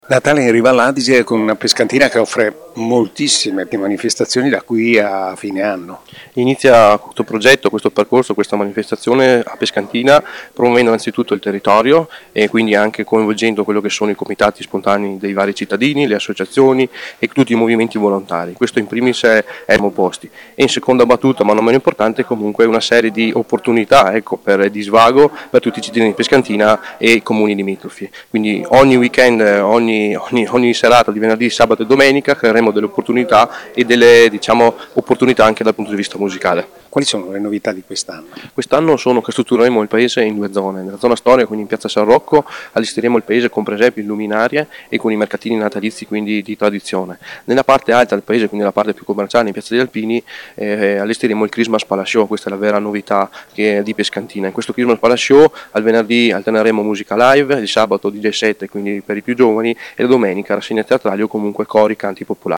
l’assessore alle Manifestazioni di Pescantina, Nicolò Rebonato
Nicolò-Rebonato-assessore-alle-manifestazioni-comune-di-Pescantina-natale-in-riva-allAdige-1.mp3